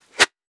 weapon_bullet_flyby_02.wav